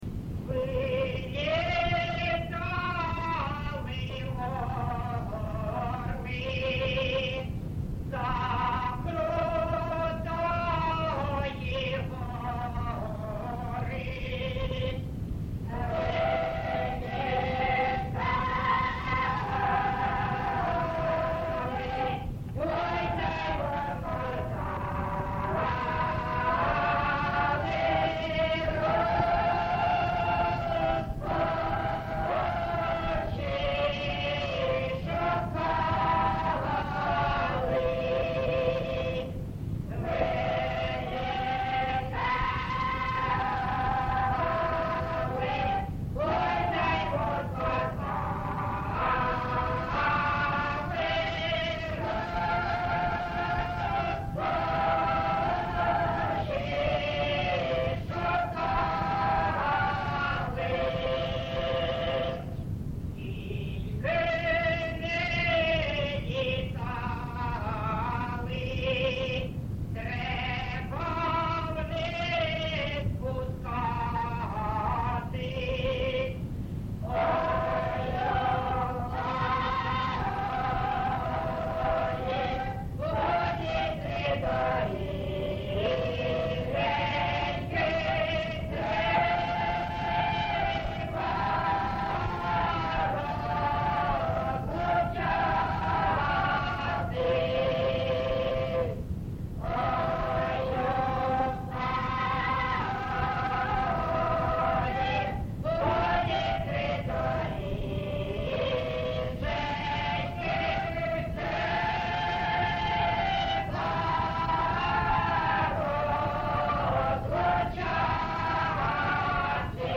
ЖанрПісні з особистого та родинного життя
Місце записус. Семенівка, Краматорський район, Донецька обл., Україна, Слобожанщина